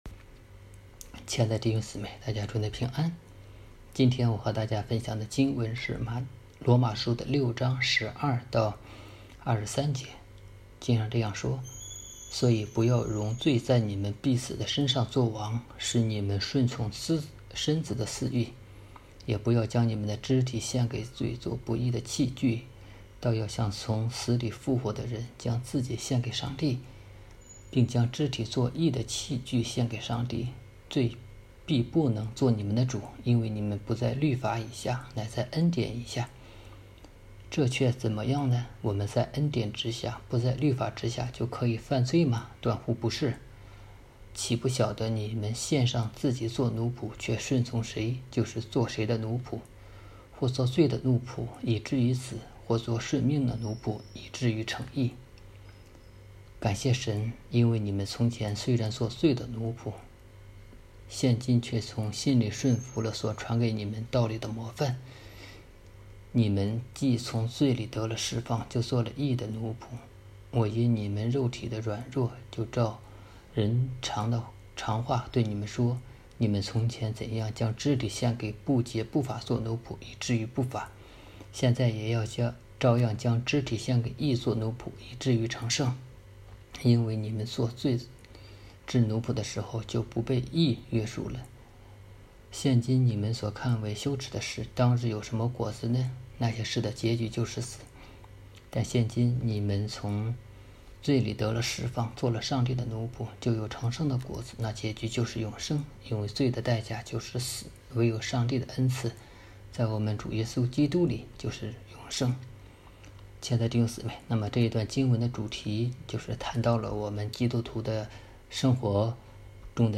《基督徒的生活-成圣》 证道